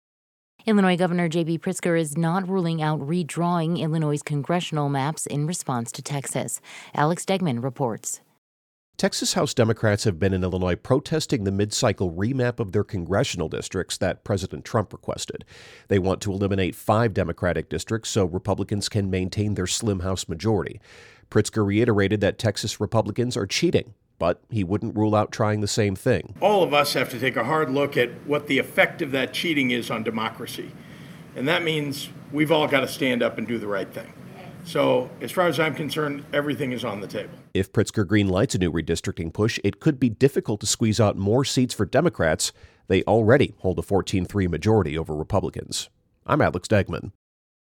Democratic National Committee Chair Ken Martin speaks at a news conference in Aurora Tuesday, with Illinois Gov. JB Pritzker behind him and alongside Texas Democrats who left their state to thwart Texas Gov. Greg Abbott’s remap efforts.